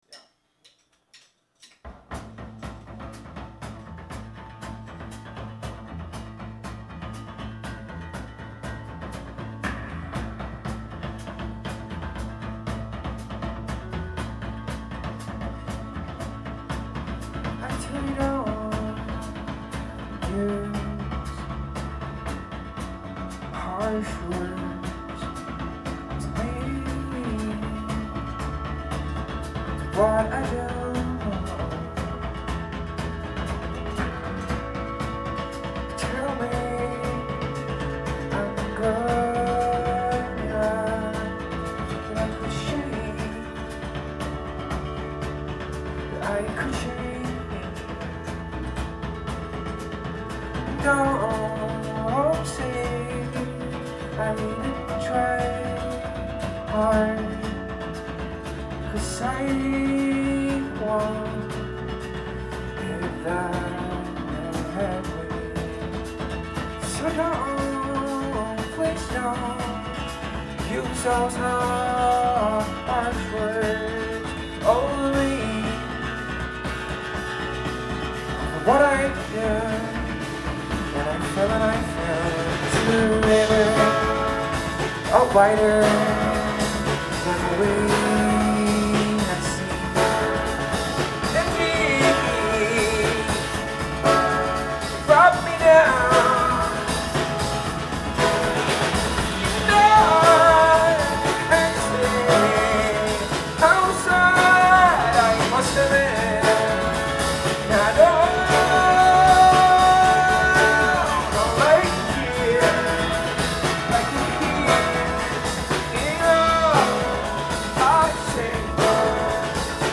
Live at Great Scott